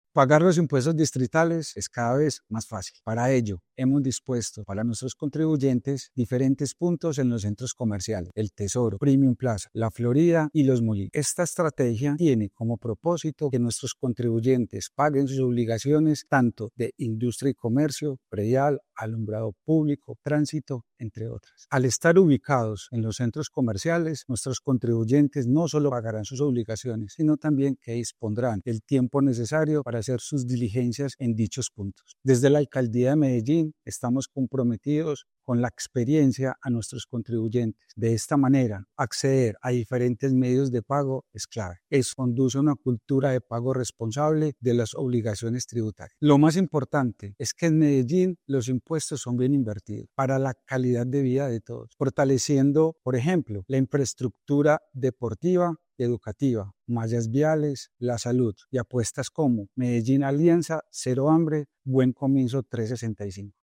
Palabras de Jorge Iván Brand Ortiz, subsecretario de Tesorería